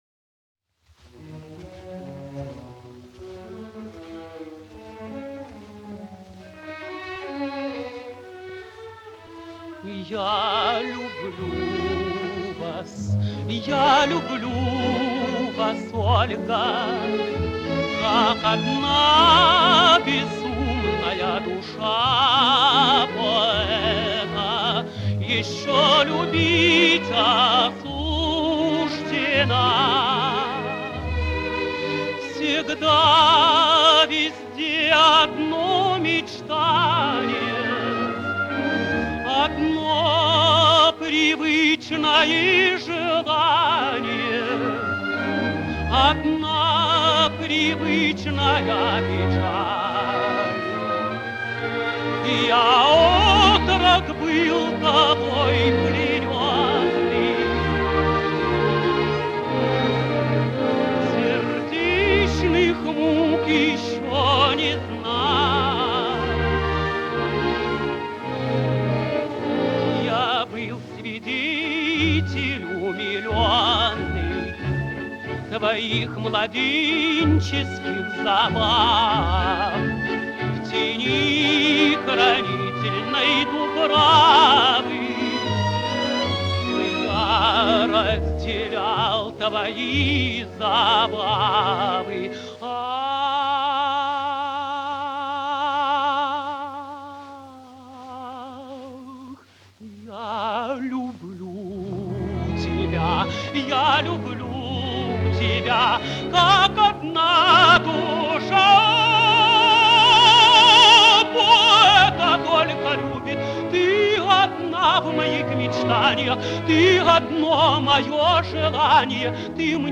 Обладал красивым, «полётным», свободно звучащим голосом, особенно в верхнем регистре.
Ариозо Ленского. Оркестр Большого театра.